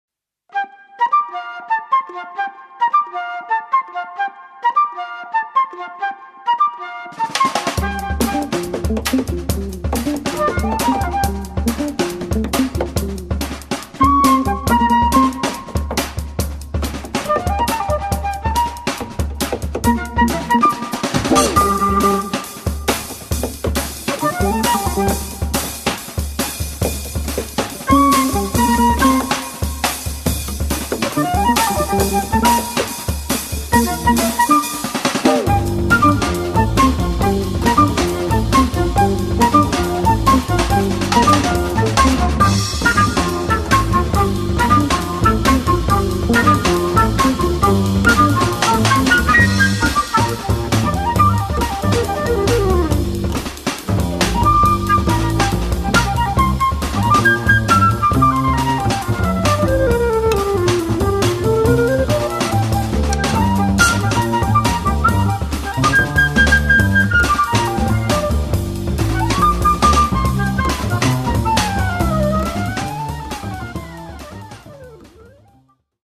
Flauto
Chitarra
Contrabbasso
Batteria
in cui il tema viene esposto all'unisono